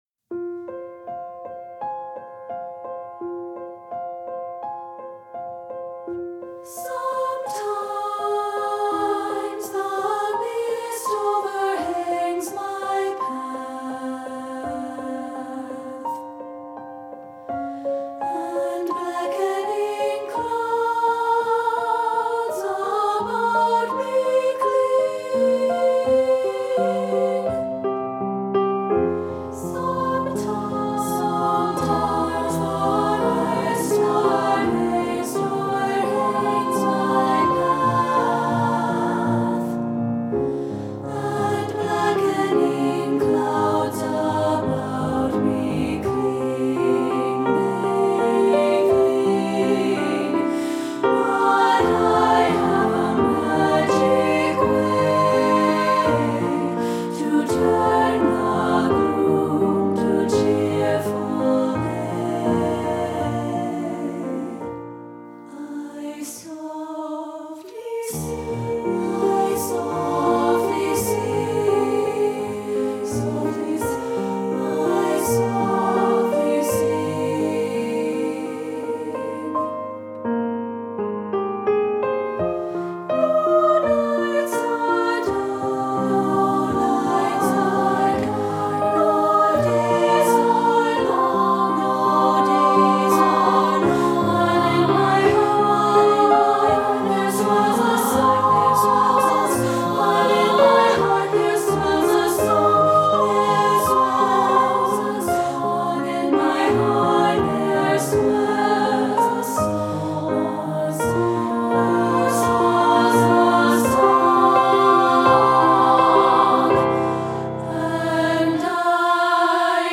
Choral Concert/General Women's Chorus
SSA